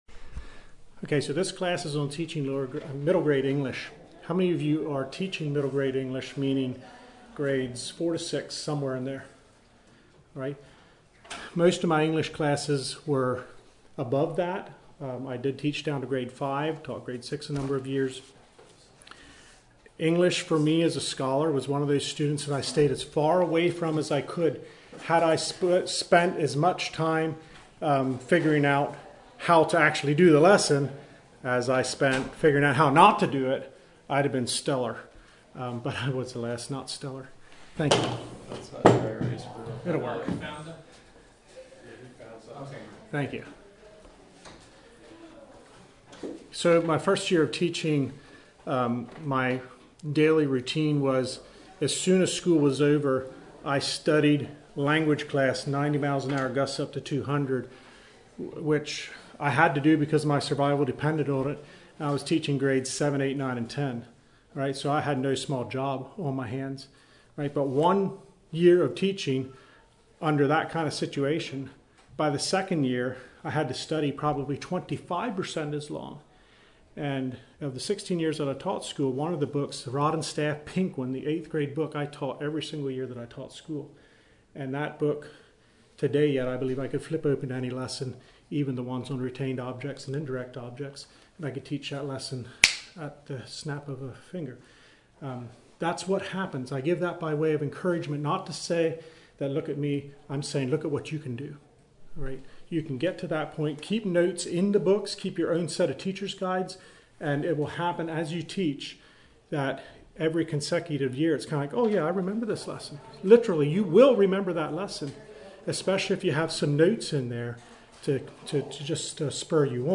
Home » Lectures » Middle Grades English